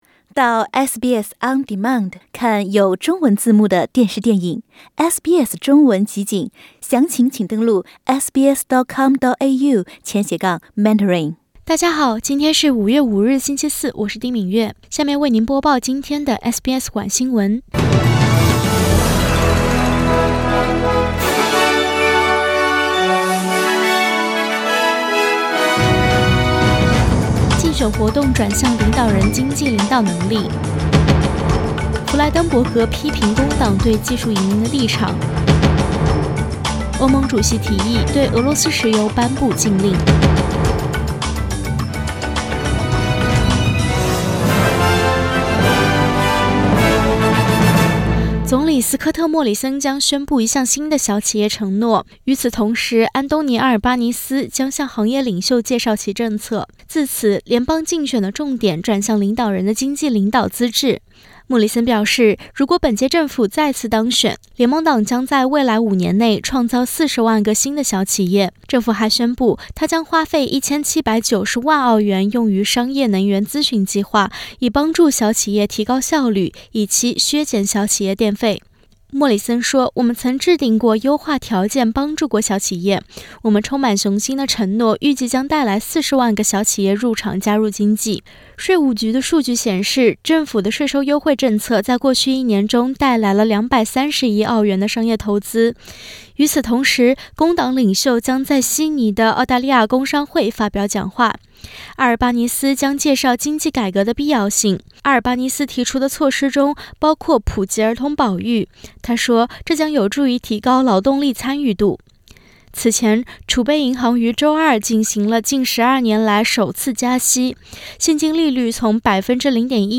SBS Mandarin evening news Source: Getty Images